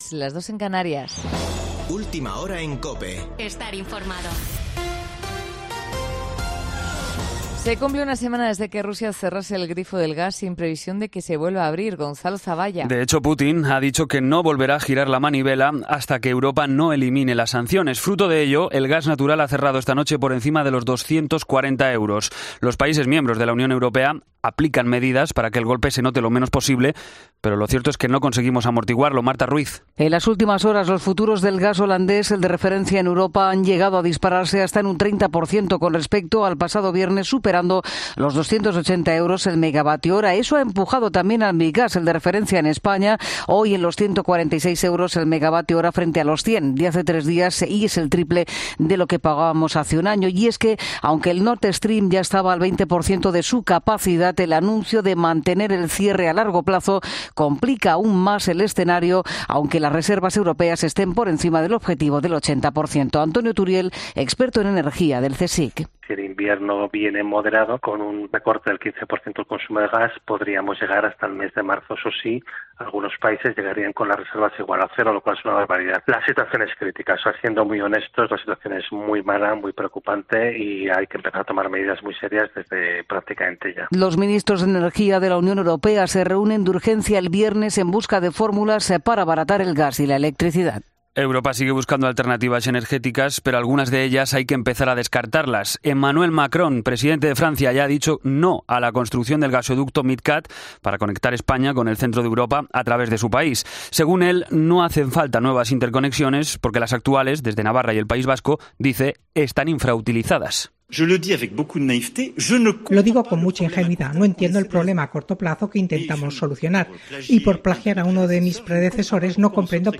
Boletín de noticias COPE del 6 de septiembre a las 03:00 horas